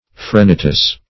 Phrenitis \Phre*ni"tis\, n. [L., fr. Gr. freni^tis, fr. frh`n,